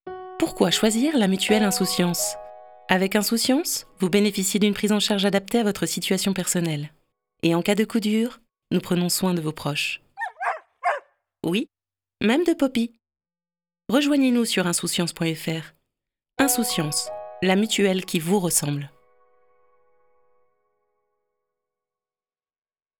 Enregistrements qualité studio
Voix publicité
voix-rassurante.wav